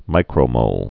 (mīkrō-mōl)